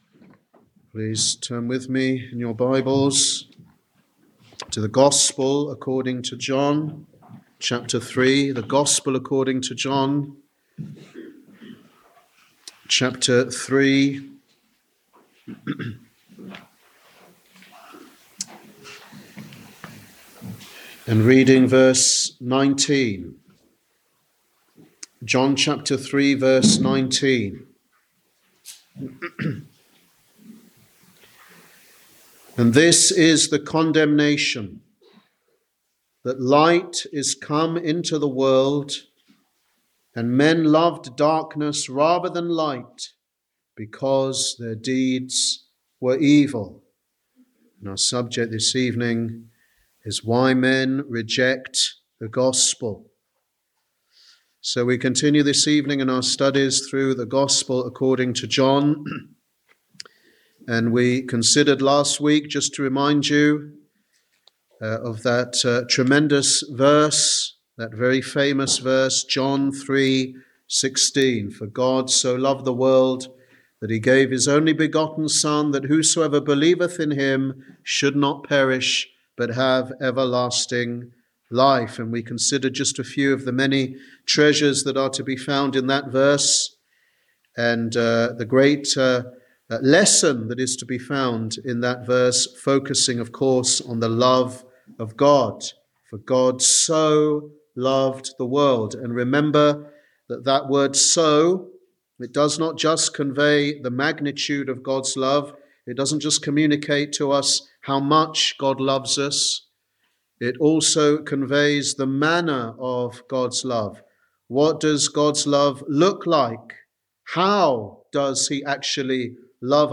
Sunday Teaching Ministry
Sermon